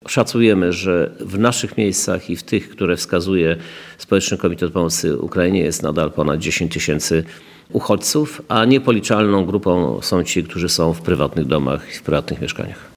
– Ukraińcy z Lublina wyjeżdżają, ale jest też spora grupa, która przyjechała i się stąd nie rusza – mówi prezydent Lublina, Krzysztof Żuk.